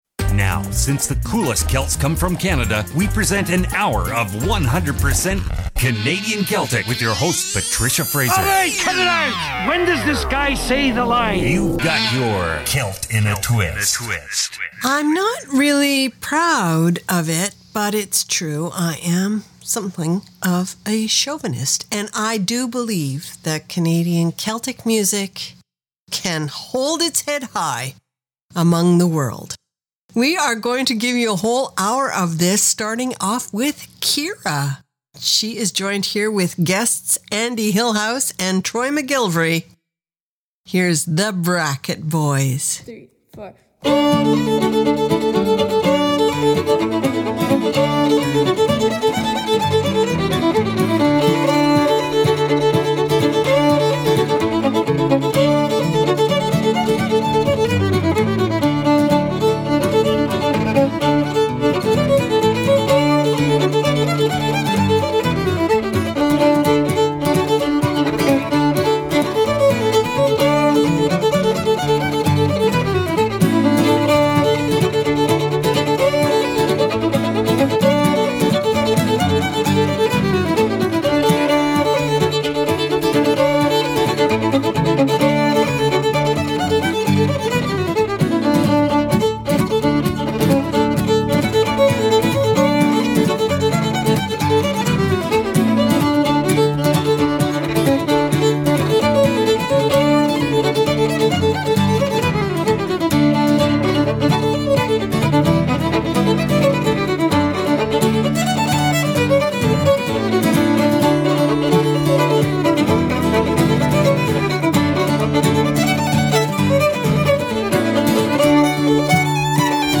CDN Celtic